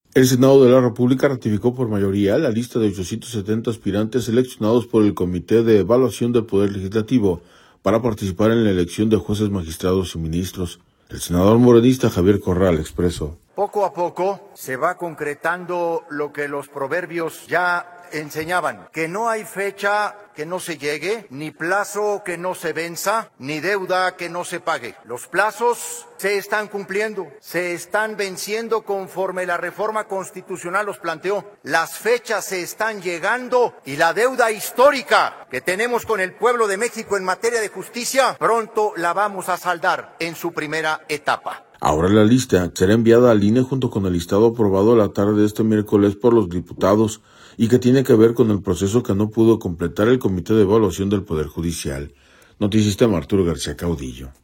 audio El Senado de la República ratificó por mayoría, la lista de 870 aspirantes seleccionados por el Comité de Evaluación del Poder Legislativo para participar en la elección de jueces, magistrados y ministros. El senador morenista, Javier Corral, expresó.